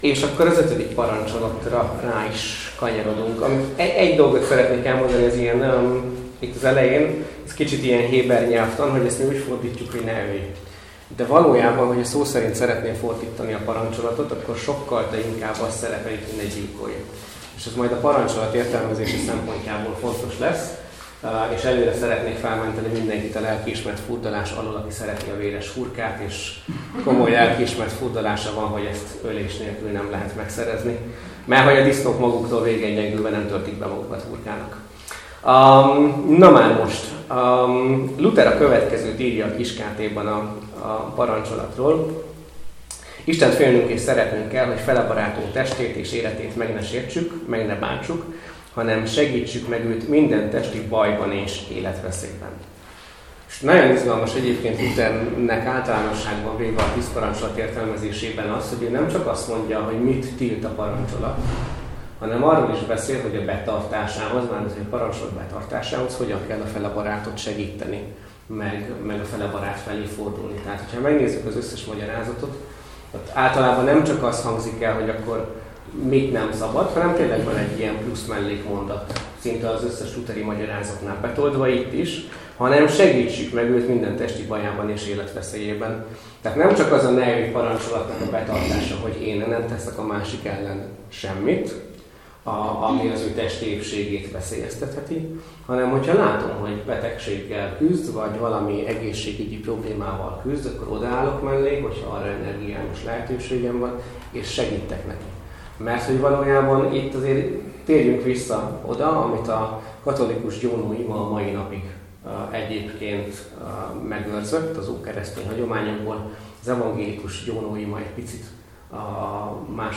A tízparancsolat - Felnőtt hittan Hegyeshalomban